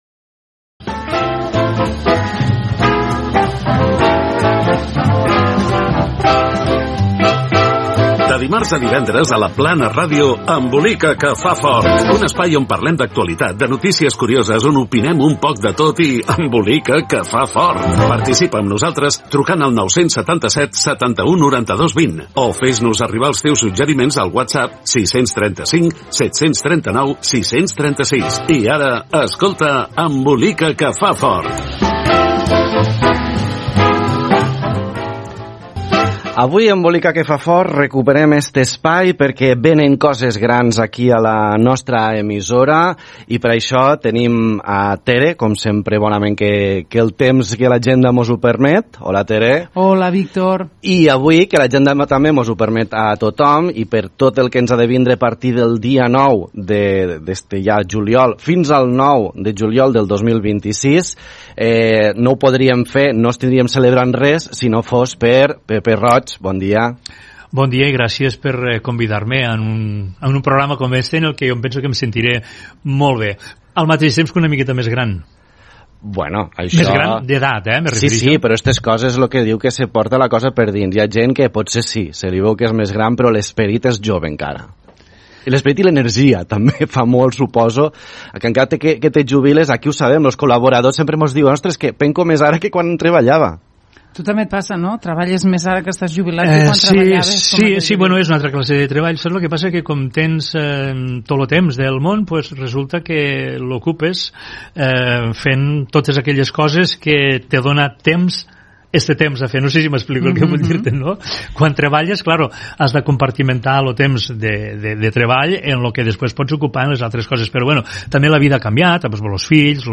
Explica com es va crear aquesta emissora municipal i el material d'un estudi de ràdio Gènere radiofònic Entreteniment